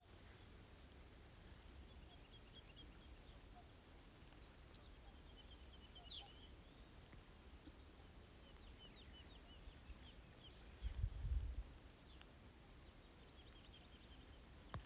Birds -> Larks ->
Woodlark, Lullula arborea
StatusSinging male in breeding season